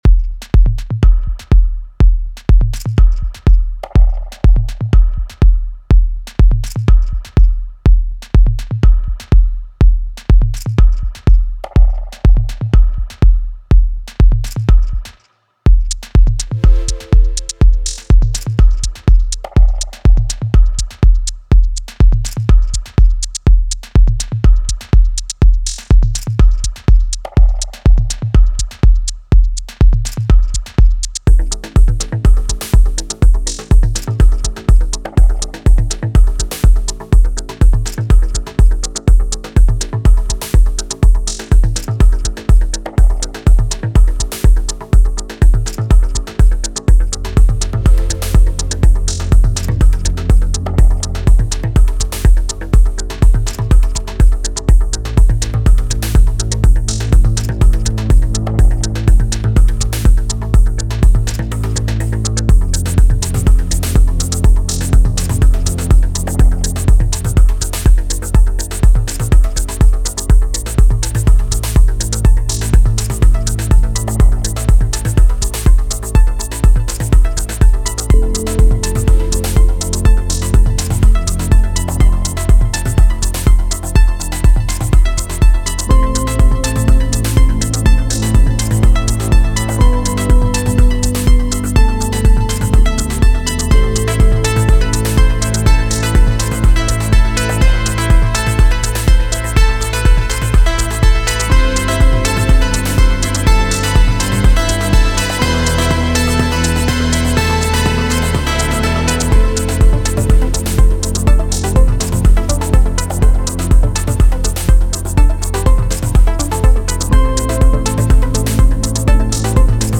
very versatile & facetted, yet rich and warm
Kicks: 15
– Piano Chords